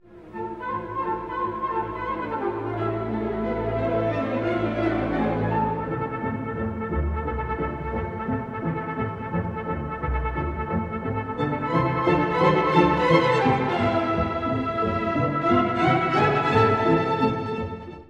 民族舞踊のタランテラです。
しだいにタンバリン、シンバル、大太鼓などが加わり、全体的な重量感も増していきます。